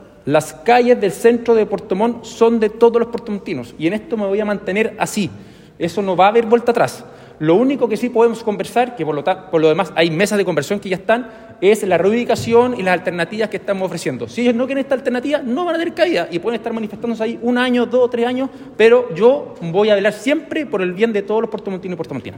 Al respecto, el alcalde, Rodrigo Wainraihgt, señaló que no hay otra alternativa que la reubicación.
rodrigo-wainraihgt-alcalde-cuna-1.mp3